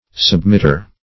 Submitter \Sub*mit"ter\, n.